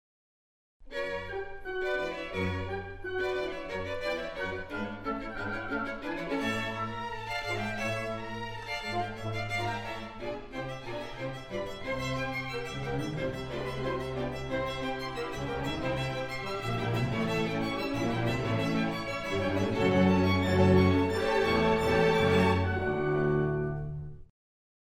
【小提琴1】
xiaotiqin1.mp3